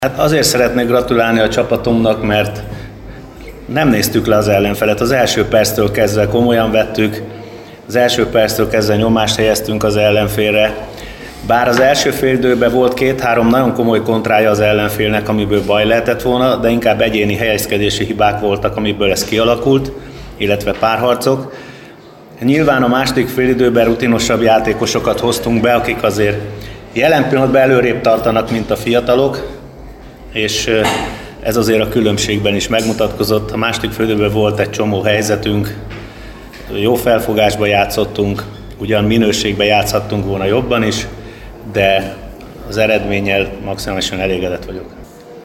A mérkőzést követő sajtótájékoztatón Bognár György vezetőedző így értékelt.